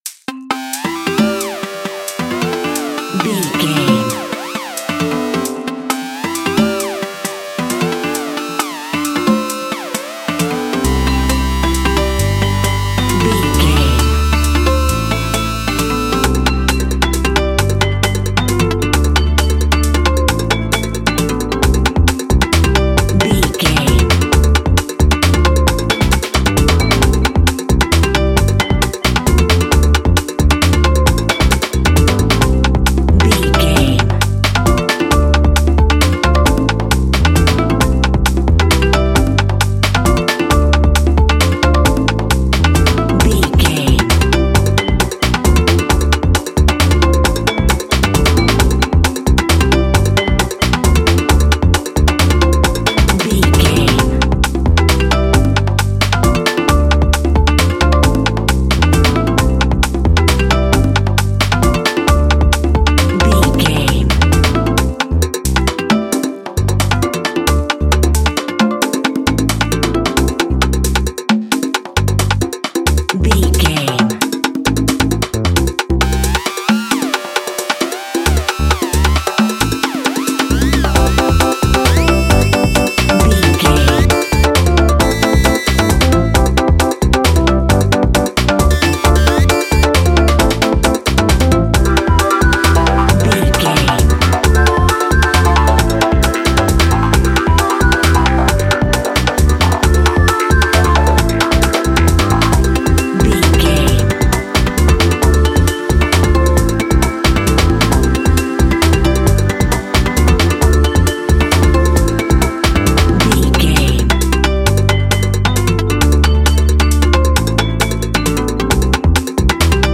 Its layered rhythms drive conviviality and dynamic movement.
Fast paced
Ionian/Major
energetic
Rhythmic
Pulsating